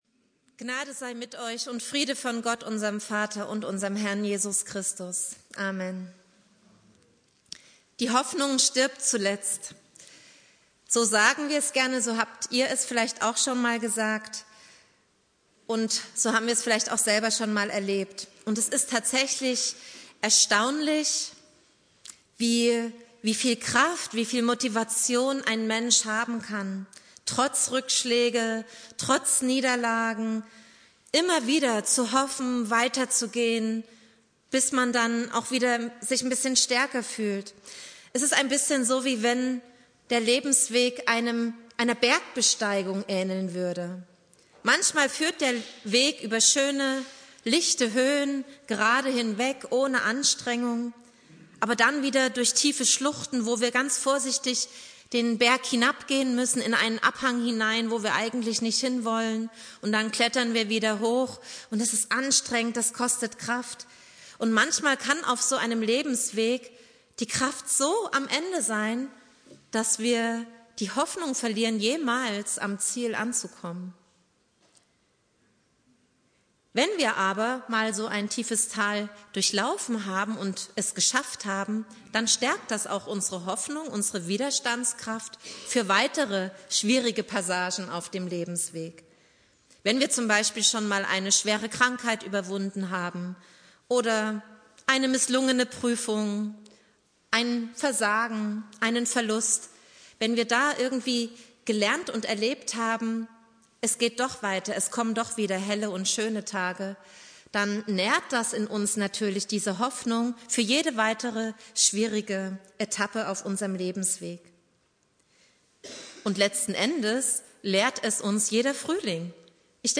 Predigt
Ostermontag Prediger